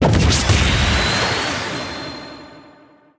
The Disc Channel jingle